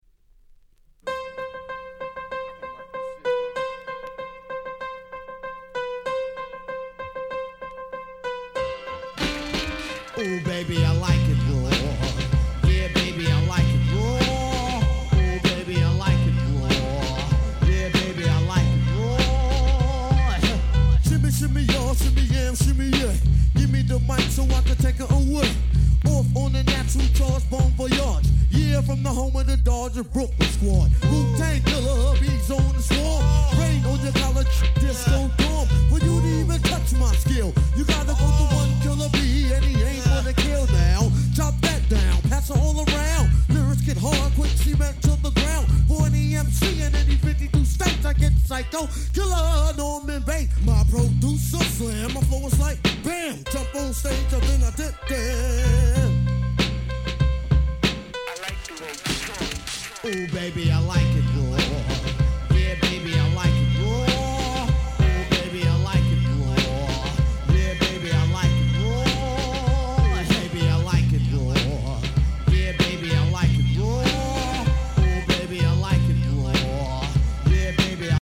これぞ90's Hip Hop !!